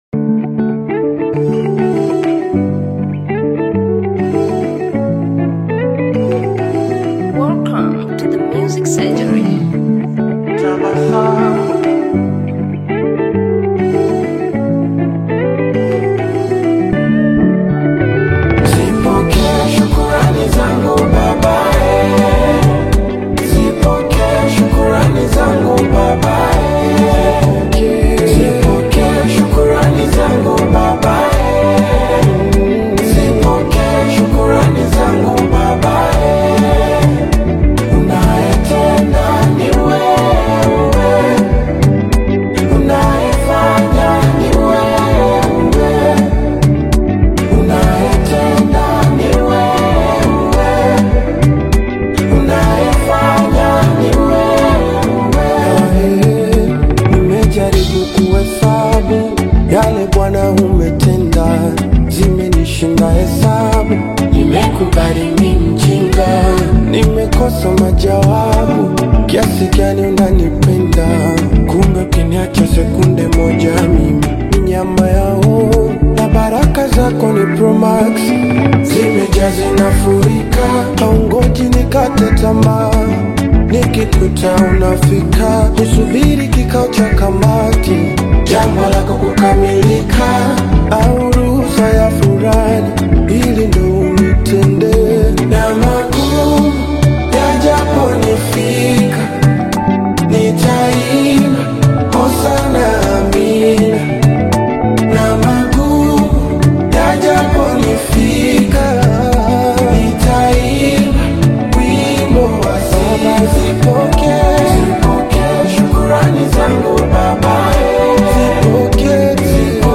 uplifting gospel single
Genre: Gospel